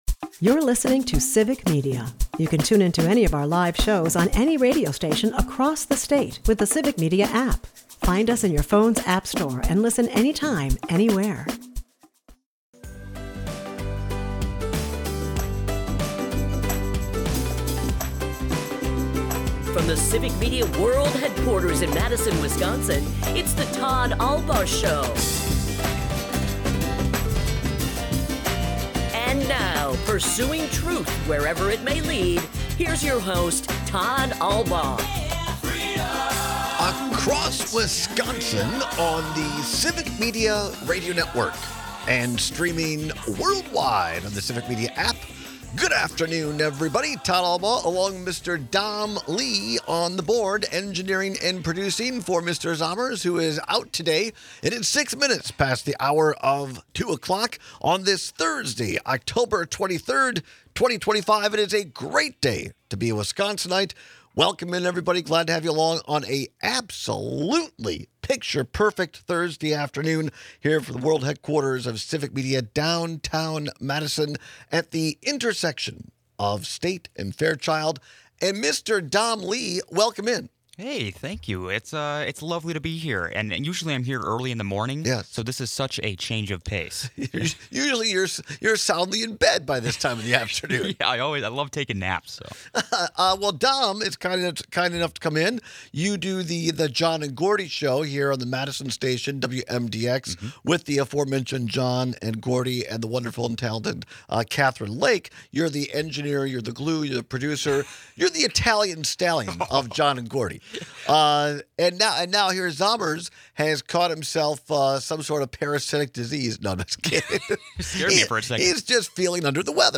In between, listeners weigh in, providing lively debate on both topics.